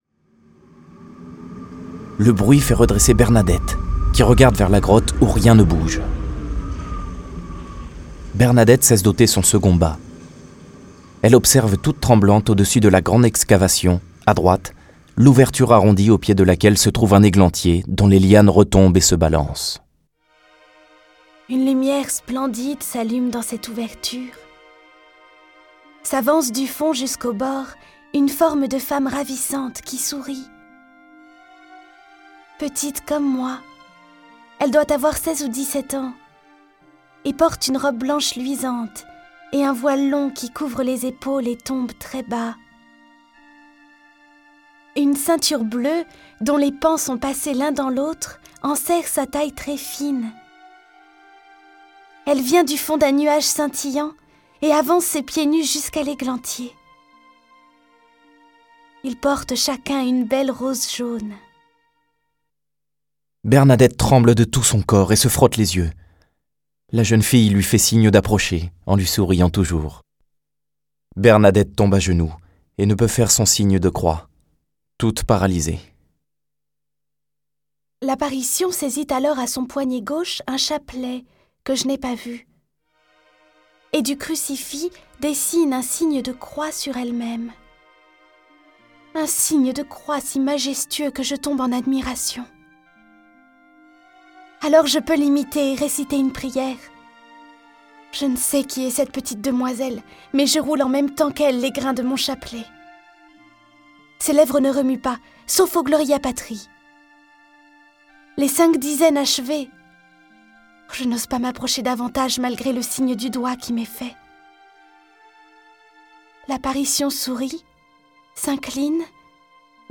Cette version sonore de la vie de Bernadette est animée par dix voix et accompagnée de plus de trente morceaux de musique classique.
Le récit et les dialogues sont illustrés avec les musiques de Bach, Beethoven, Brahms, Chopin, Debussy, Fauré, Grieg, Haendel, Mahler, Mozart, Rameau, Vivaldi, Wagner.